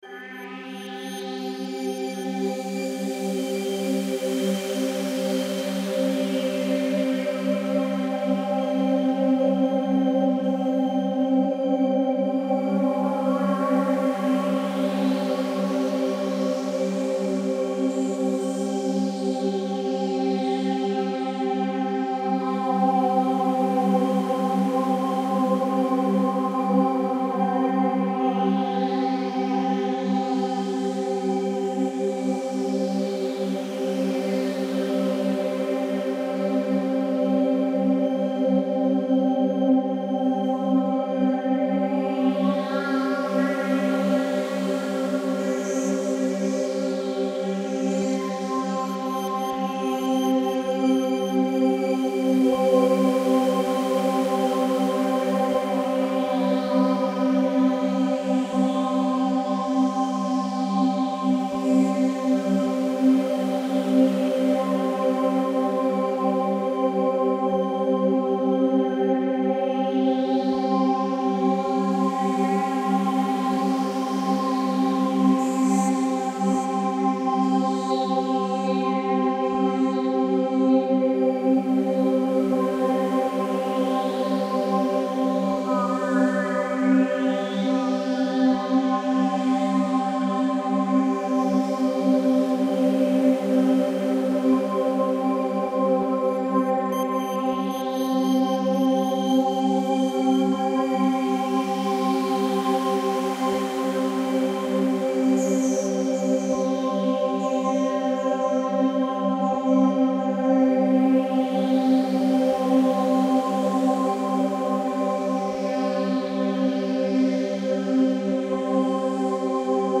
280 Hz Frequency Binaural Beats for Relaxing